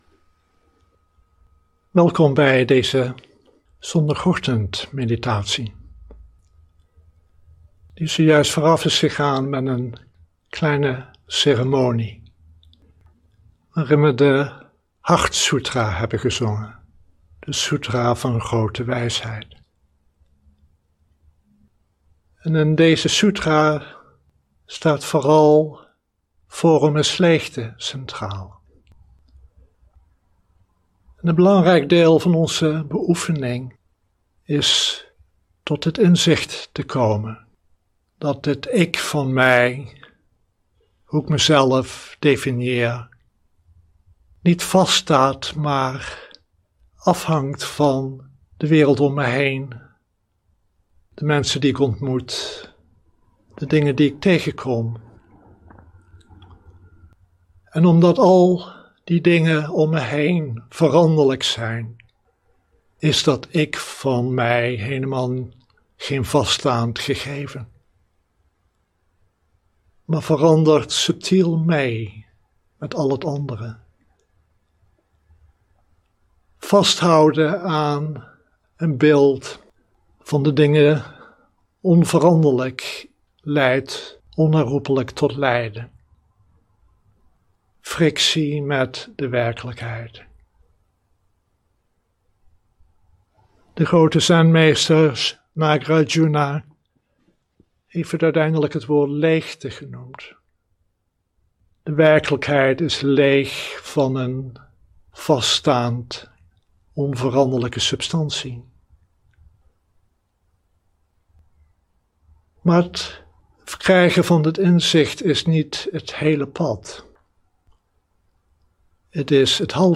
Dharma-onderwijs
Livestream opname
Welkom bij deze zondagochtendmeditatie, die zojuist vooraf is gegaan met een kleine ceremonie waarin we de Hartsoetra hebben gezongen, de Soetra van Grote Wijsheid.